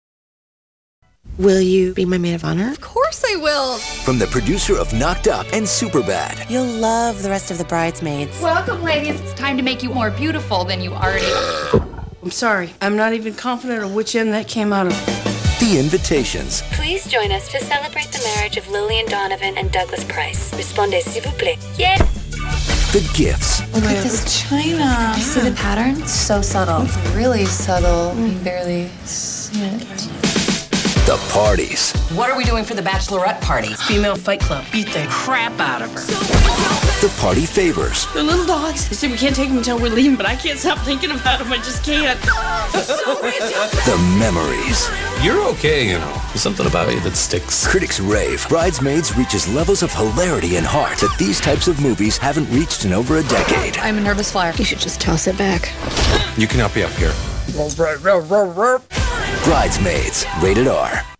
Brides Maids TV Spots